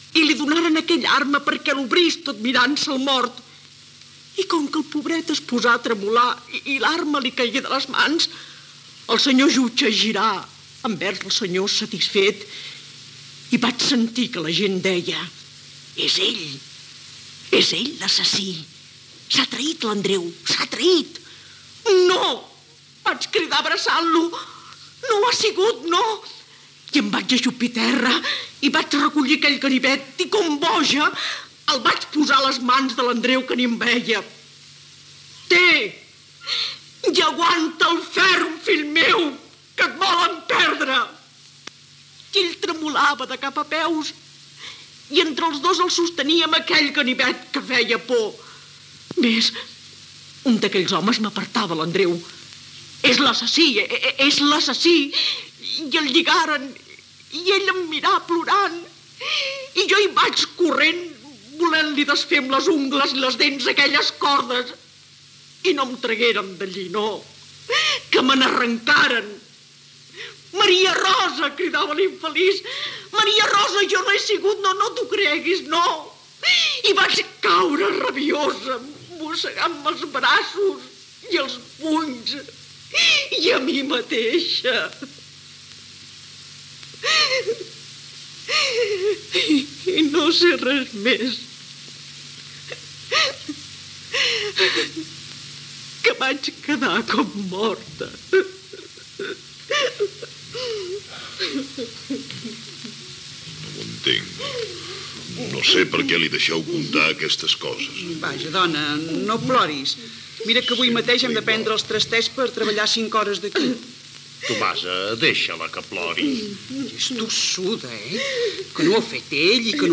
Adaptació radiofònica de "Maria Rosa" d'Àngel Guimerà. Maria Rosa explica plorosa el que va passar el dia de l'assassinat i vol anar a veure al seu marit, Andreu, a la presó de Ceuta
Ficció